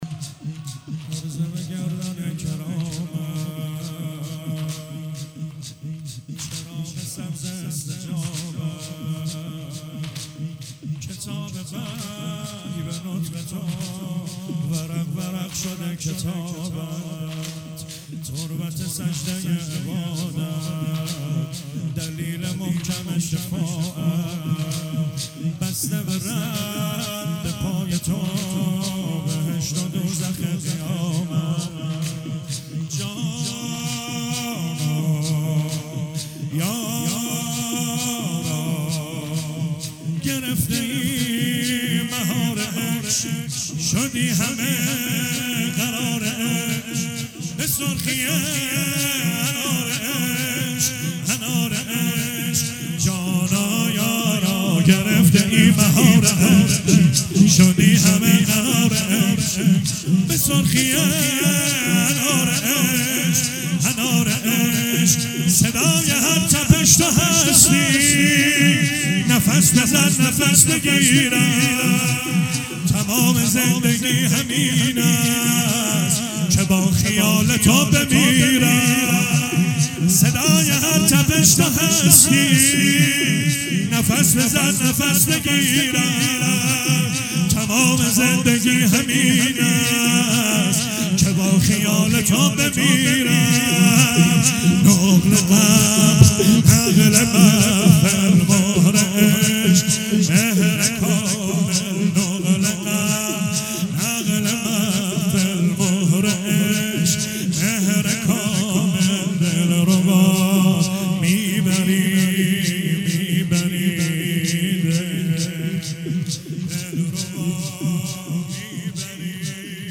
ایام فاطمیه 97 - یزد - شور - حرز به گردن کرامت